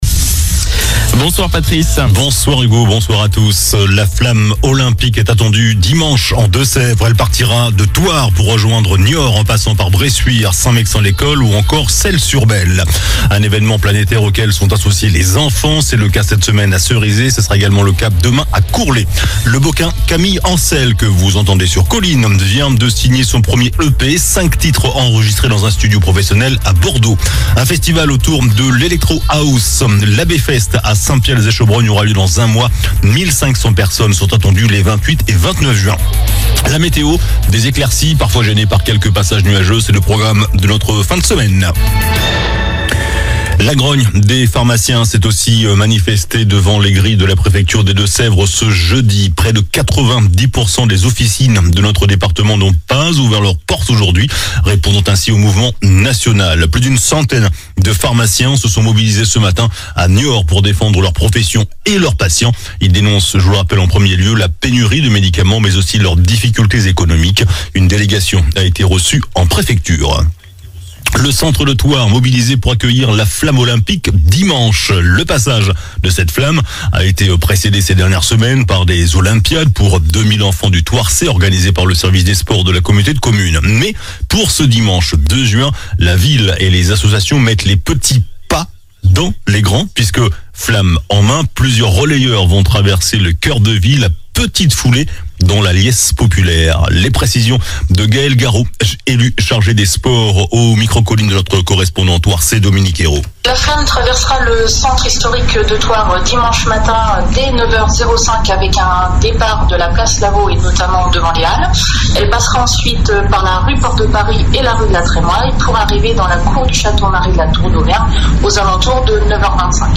JOURNAL DU JEUDI 30 MAI ( SOIR )